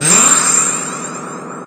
Ice6.ogg